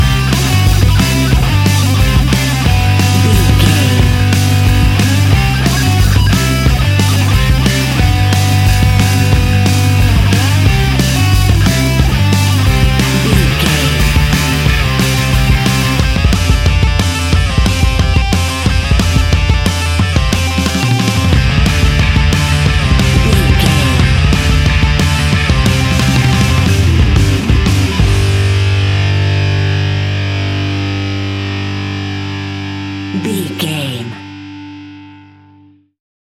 Epic / Action
Fast paced
Ionian/Major
hard rock
distortion
punk metal
instrumentals
Rock Bass
Rock Drums
heavy drums
distorted guitars
hammond organ